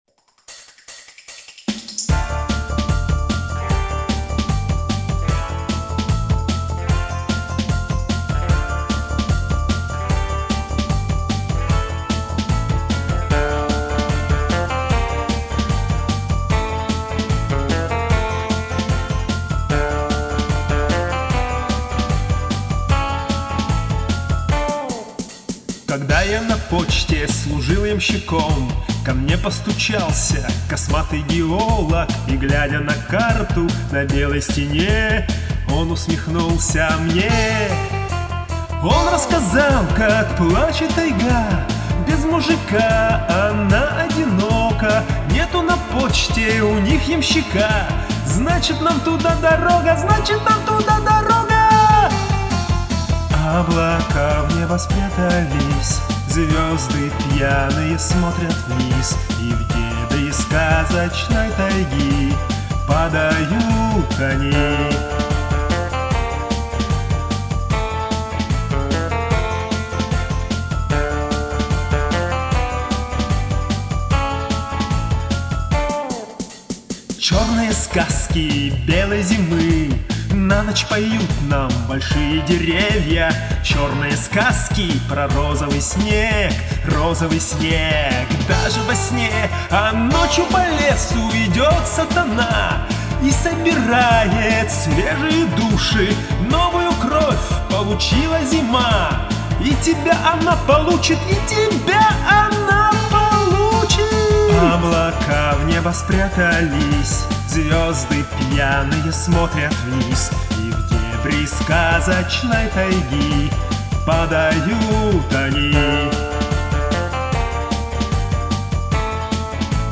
ТЫ ПРОТЯГИВАЕШЬ ОКОНЧАНИЯ СЛОВ